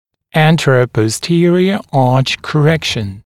[ˌæntərəpɔs’tɪərɪə ɑːʧ kə’rekʃn][ˌэнтэрэпос’тиэриэ а:ч кэ’рэкшн]исправление соотношения зубных дуг в переднезаднем направлении, сагитальная коррекция соотношения зубных рядов